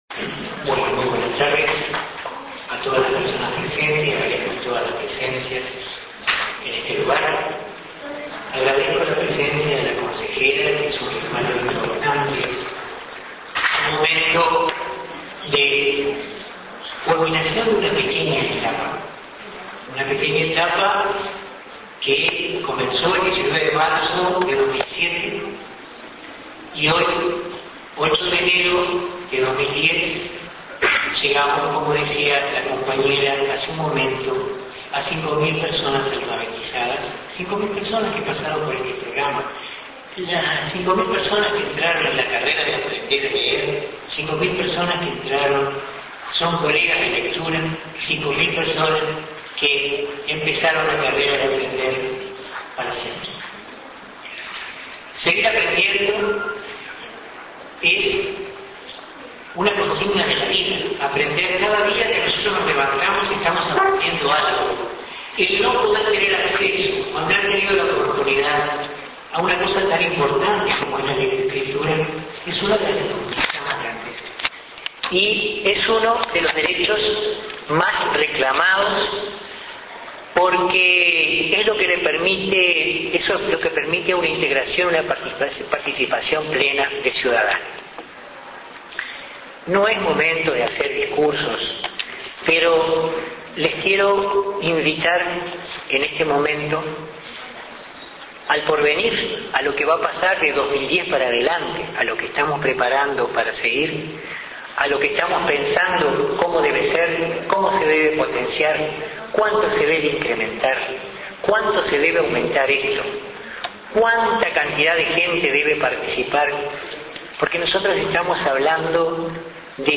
en la graduación de participantes del Programa “En el país de Varela. Yo sí puedo”.